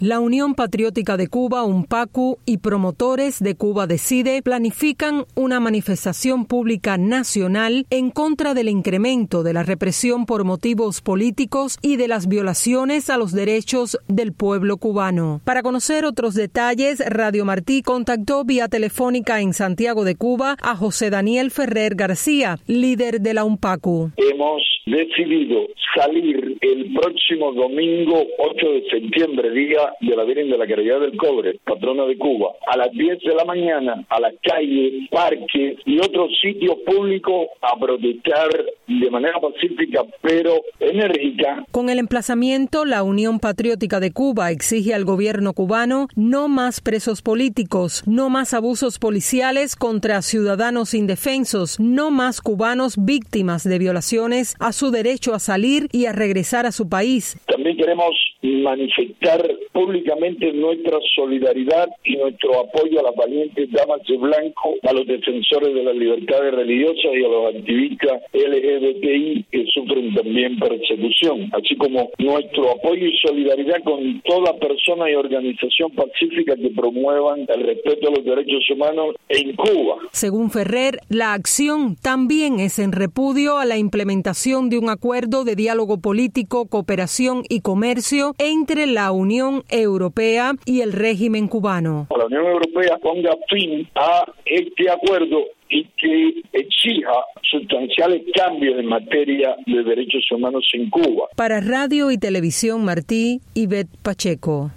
Entrevista a José Daniel Ferrer | UNPACU convoca